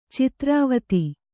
♪ citrāvati